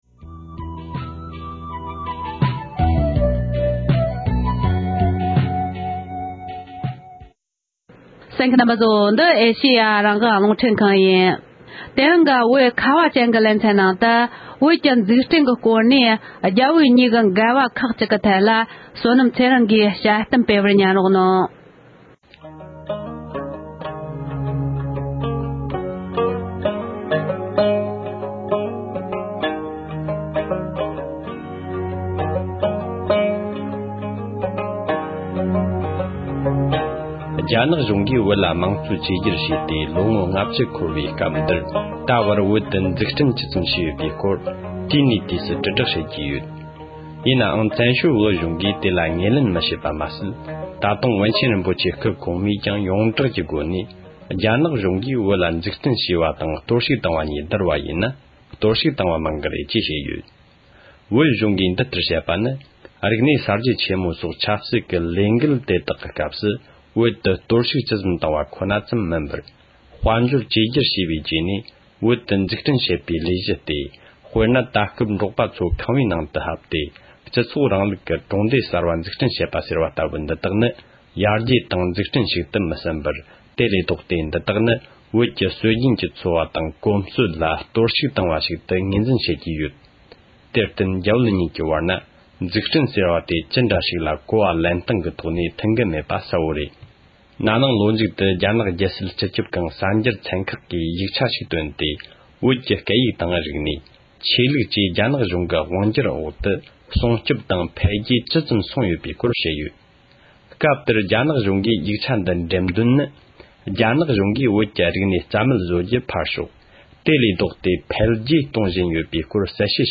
བོད་ཀྱི་འཛུགས་སྐྲུན་གྱི་སྐོར་ནས་རྒྱ་བོད་གཉིས་ཀྱི་འགལ་བ་ཁག་གི་ཐད་དཔྱད་གཏམ་སྤེལ་བ།